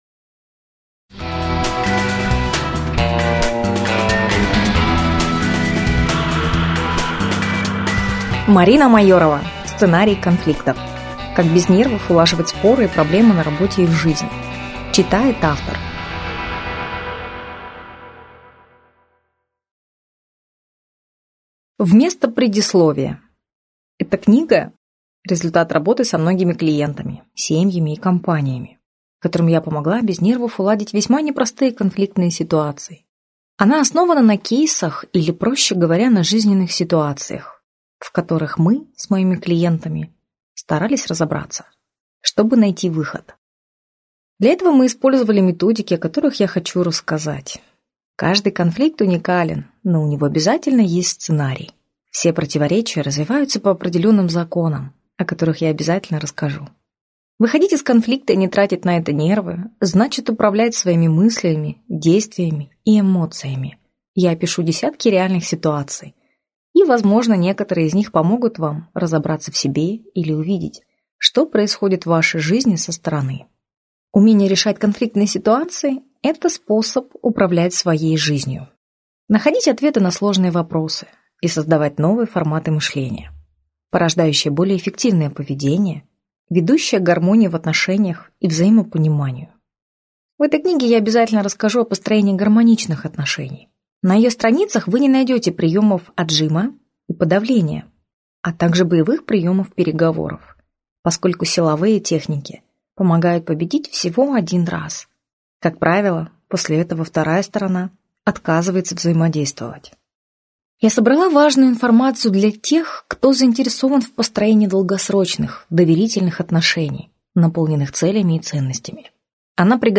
Аудиокнига Сценарии конфликтов. Как без нервов улаживать споры и проблемы на работе и в жизни | Библиотека аудиокниг